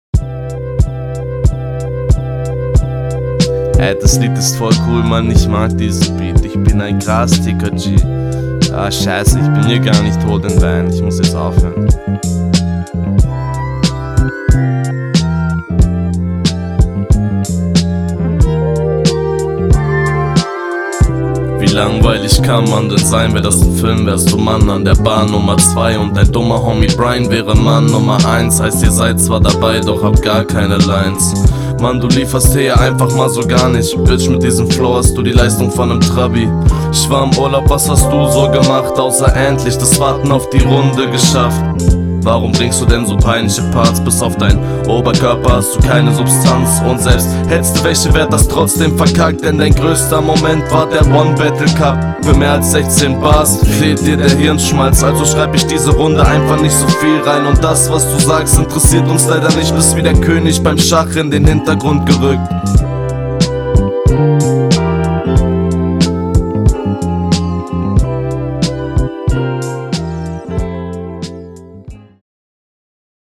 Der Stimmeinsatz ist okay, aber der Dude schläft ja gleich ein.
Flow: Sehr entspannt, kommt echt chillig rüber Text: Schachline war cool, Mann Nummer 2 war …